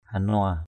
/ha-nʊa/ (d.) chim trau trảu.